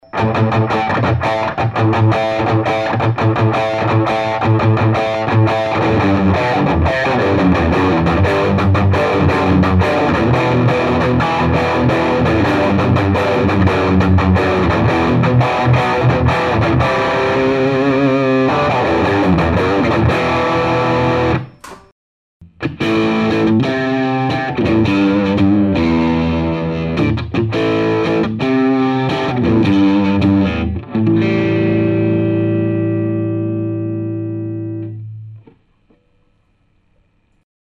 Un sample tout chaud, c est court mais assez fidèle à l'ampli je pense.
C est pas du direct out mais repiqué au micro des Hp d'un combo JVM.
La premiere partie c est un Seymour Duncan SH4 en position chevalet .
La deuxieme partie c est un Bill Lawrence L500XL splité en positon manche